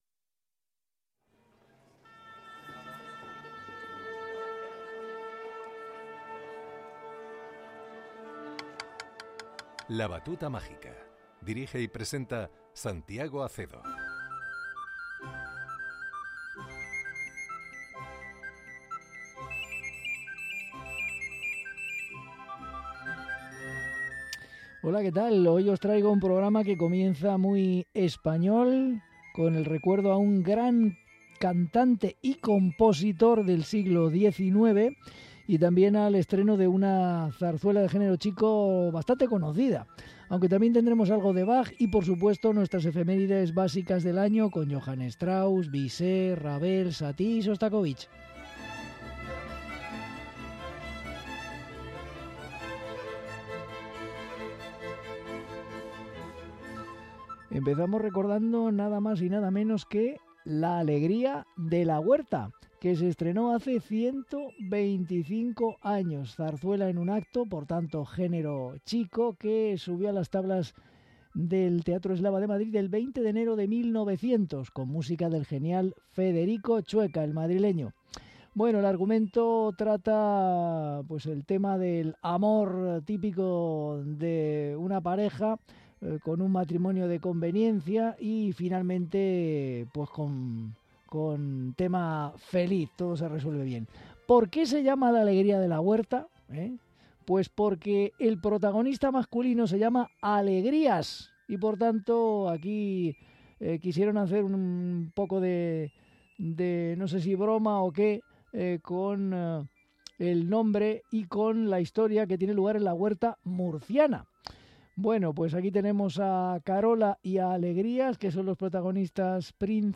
zarzuela
Dúo de Soprano y Contralto
Cuarteto de cuerda en Fa Mayor